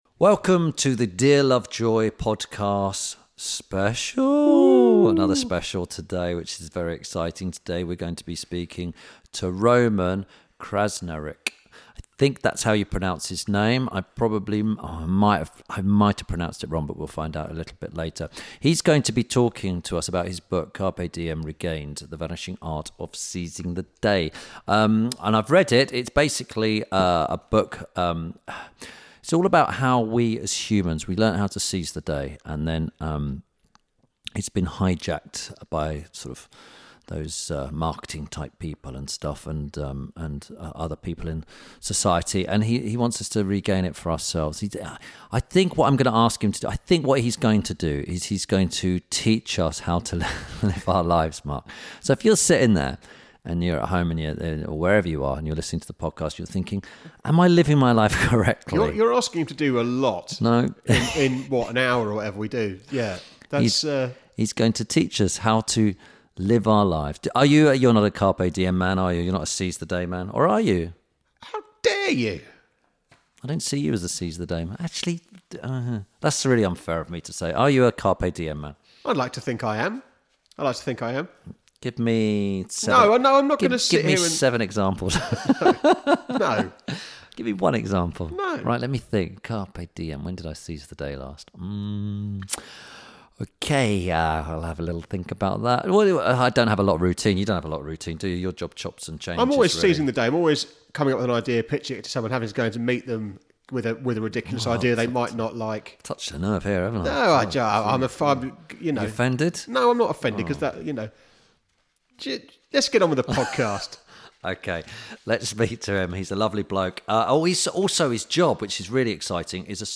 Ep. 33 – ROMAN KRZNARIC - Roman Will Teach You How To Live Your Life Better – INTERVIEW SPECIAL.
Tim Lovejoy chats to social philosopher and author Roman Krznaric. They discuss his new book Carpe Diem Regained, freedom and as the title of his book suggests, how to ‘seize the day’.